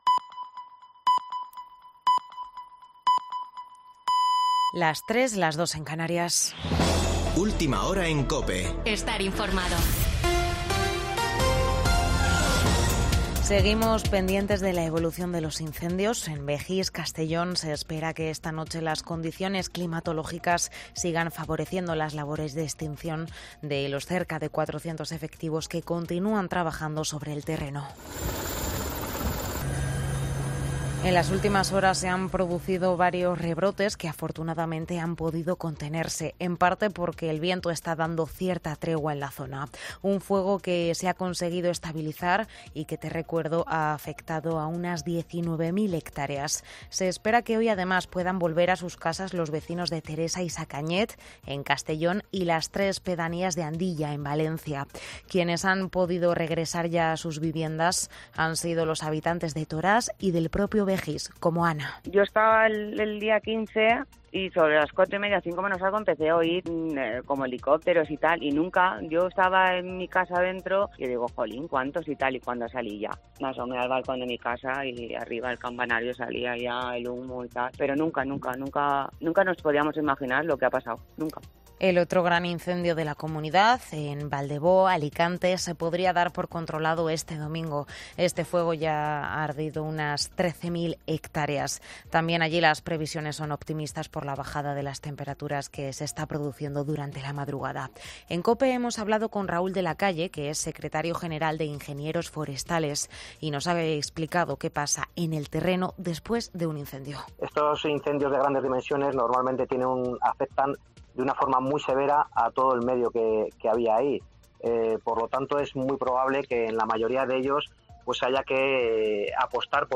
Boletín de noticias de COPE del 21 de agosto de 2022 a las 02.00 horas